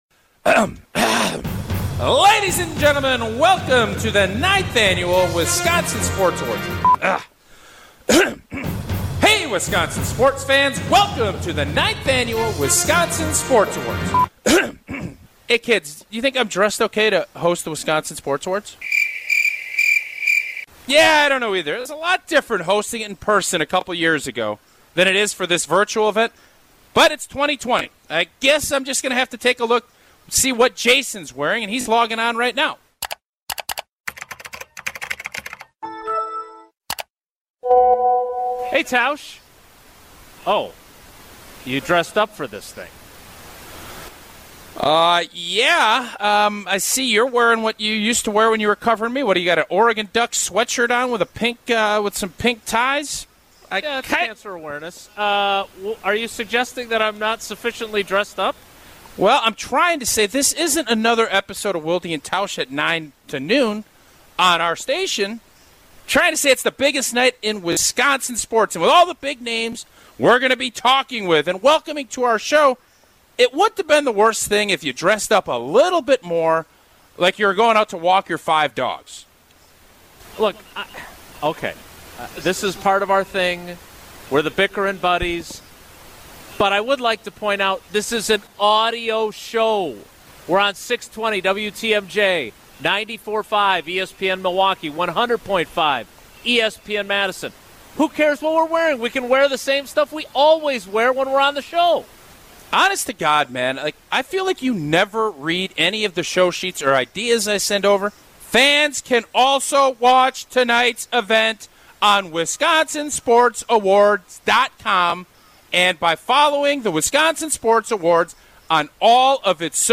It's the 9th Annual Wisconsin Sports Awards on ESPN Madison, ESPN Milwaukee, and 620 WTMJ presented by Gruber Law Offices, Cousins Subs, Potosi Brewing Company, and American Family Insurance.